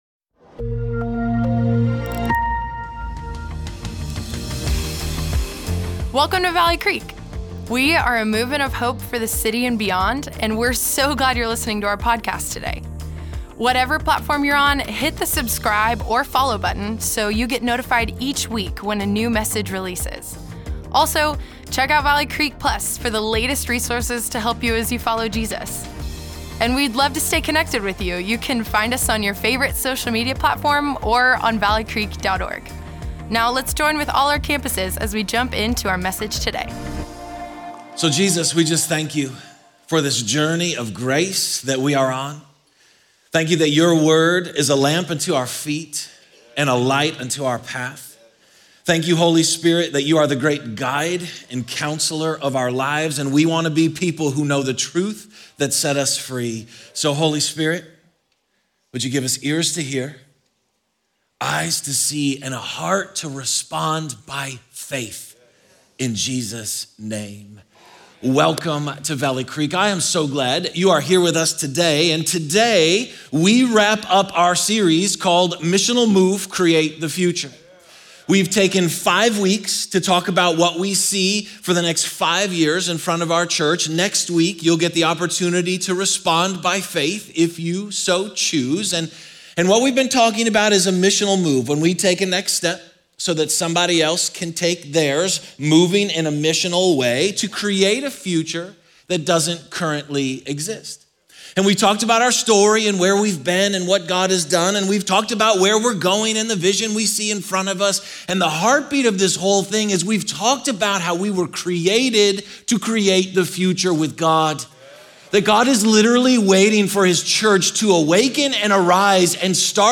Valley Creek Church Weekend Messages Tools & Treasures Feb 23 2025 | 00:54:29 1x 00:00 / 00:54:29 Subscribe Share Apple Podcasts Spotify Amazon Music Overcast RSS Feed Share Link Embed